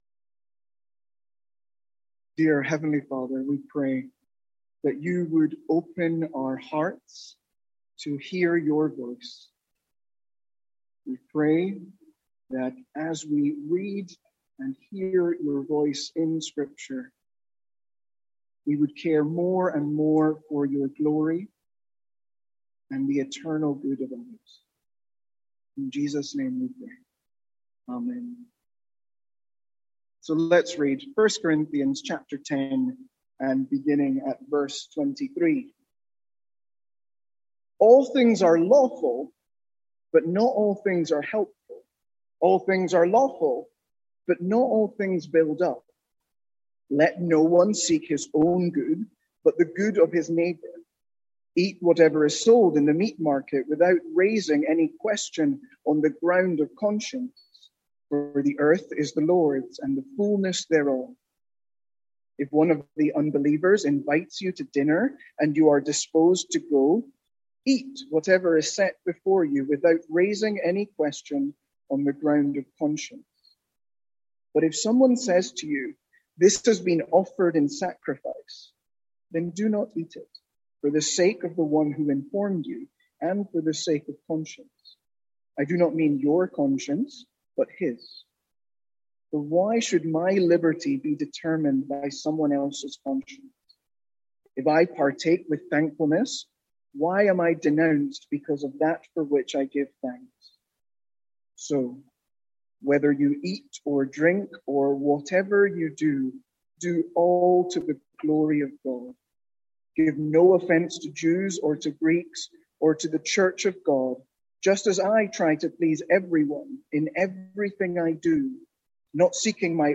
Sermons | St Andrews Free Church
From our evening series in 1 Corinthians.